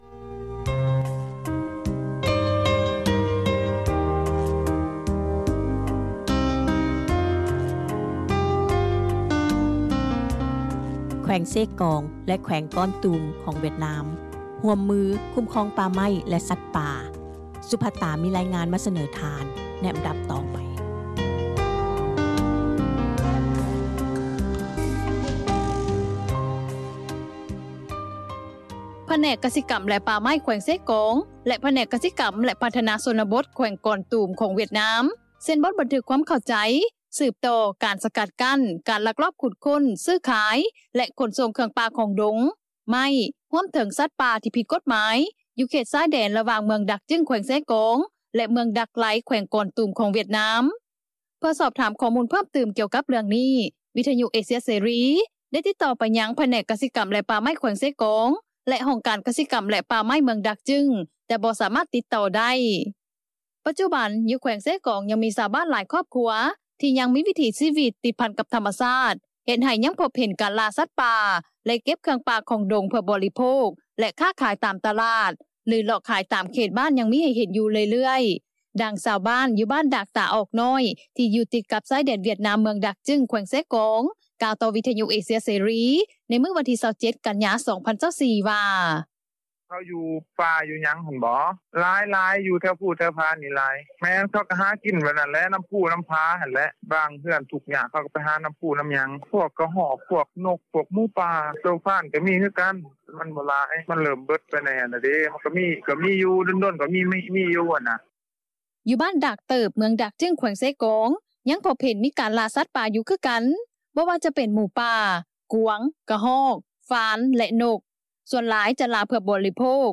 ຢູ່ບ້ານດາກເຕຼີບ ເມືອງດາກຈຶງ ແຂວງເຊກອງ ຍັງພົບເຫັນມີການລ່າສັດປ່າຢູ່ຄືກັນ ບໍ່ວ່າຈະເປັນໝູປ່າ ກວາງ ກະຮອກ ຟານ ແລະນົກ ສ່ວນຫຼາຍ ຈະລ່າ ເພື່ອບໍລິໂພກ ແລະຈະຄ້າຂາຍ ຢູ່ບໍລິເວນໃນເຂດບ້ານເທົ່ານັ້ນ ຍ້ອນກົດລະບຽບຈະບໍ່ເຄັ່ງຄັດເທົ່າໃນເມືອງ ທີ່ອາດຖືກເຈົ້າໜ້າທີ່ສະກັດກັ້ນ ແລະກັກໂຕໄວ້ໄດ້, ດັ່ງຊາວບ້ານ ຢູ່ບ້ານດາກຕຽນ ເມືອງດາກຈຶງ ທ່ານໜຶ່ງ ກ່າວວ່າ:
ກ່ຽວກັບເລື່ອງການຄ້າໄມ້ນັ້ນ ກໍ່ຍັງເຫັນ ລົດແກ່ໄມ້ທ່ອນ ໄມ້ຕັບ ແລ່ນຜ່ານຢູ່ ໂດຍສະເພາະ ໃນຊ່ວງກ່ອນລະດູຝົນ ມາປັດຈຸບັນ ບໍ່ຄ່ອຍເຫັນລົດແກ່ໄມ້ຫຼາຍປານໃດ ລົດແກ່ໄມ້ນີ້ ສ່ວນໜຶ່ງ ມາຈາກແຂວງເຊກອງ ບາງສ່ວນ ມາຈາກແຂວງອັດຕະປື ຄາດວ່າ ອາດເປັນໄມ້ລັກຕັດ ເພື່ອຂົນໄປປະເທດຫວຽດນາມ, ດັ່ງຊາວບ້ານ ຢູ່ເມືອງດາກຈຶງ ແຂວງເຊກອງ ນາງໜຶ່ງ ກ່າວວ່າ: